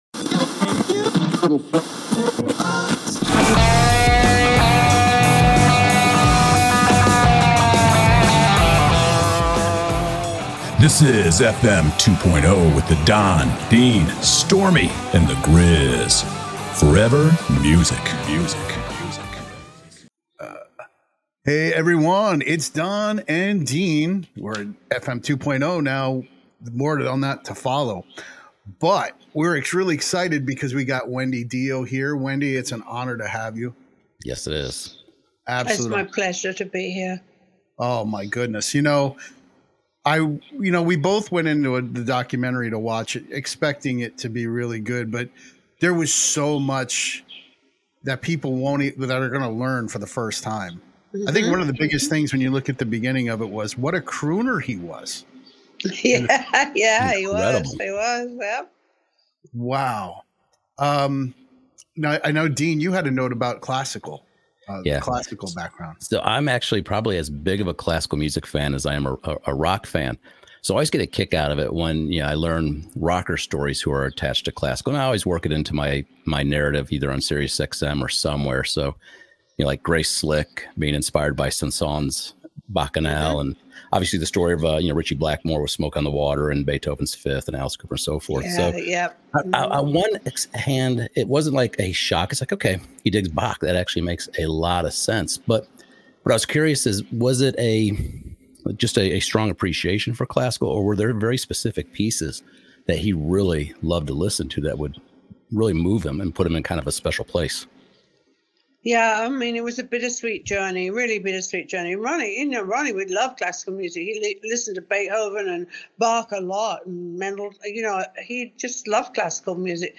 Wendy Dio Interview: Ronnie's Dreams Will Never Die on FM 2.0 Conversations